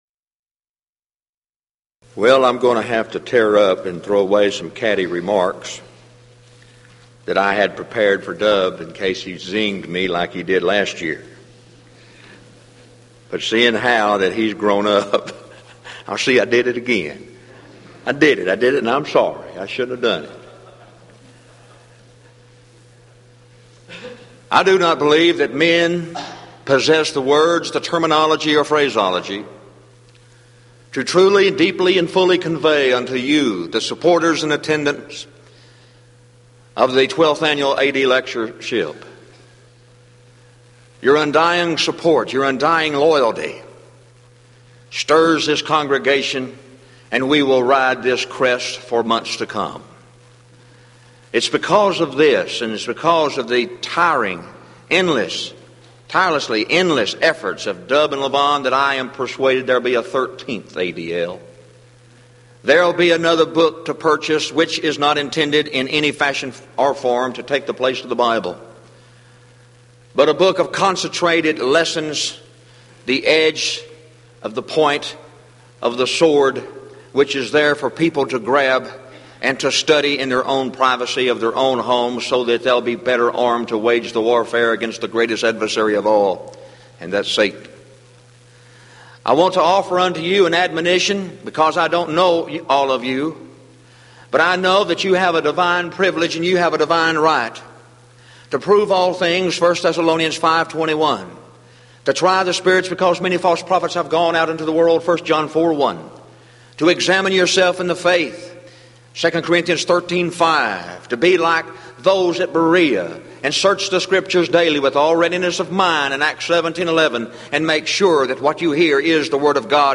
Event: 1993 Denton Lectures
lecture